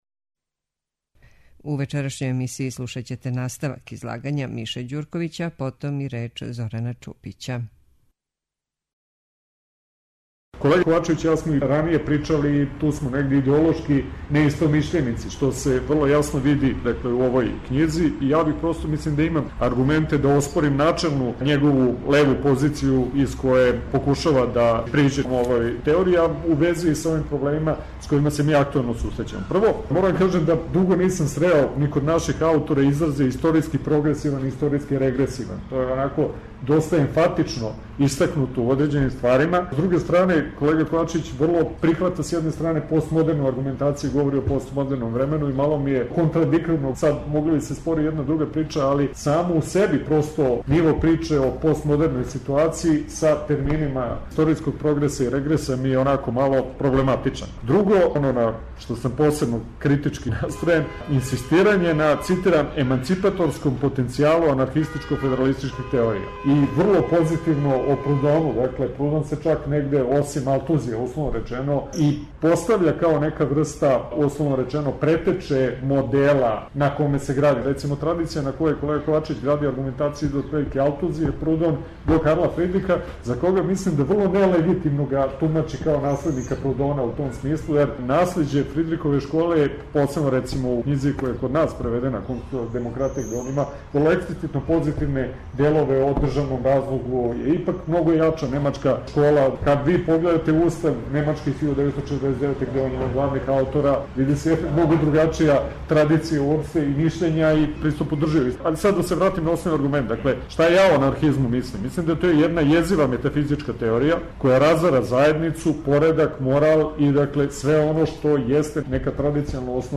Разговор о књизи организовао је Институт за европске студије у Београду крајем априла ове године.